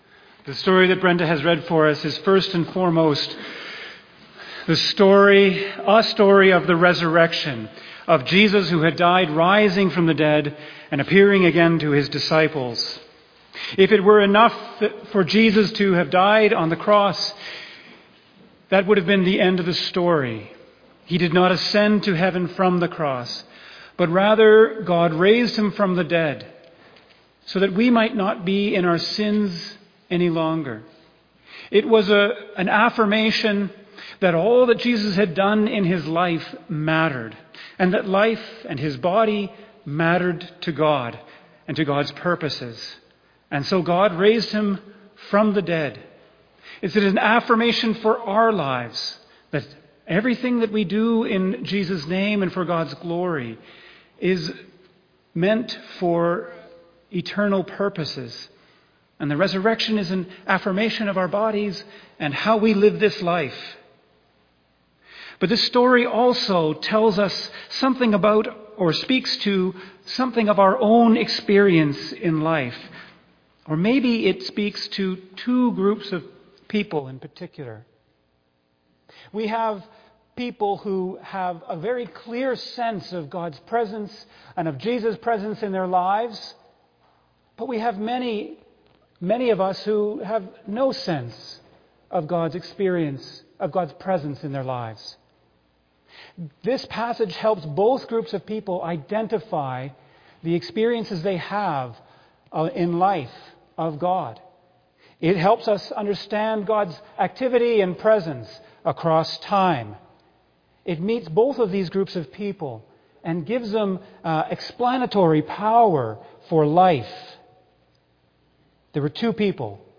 2025 Sermon October 26 2025